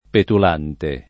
petulante [ petul # nte ] agg.